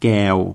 แกว [gae:o--]for example, we have กว in a row, but it is not a cluster.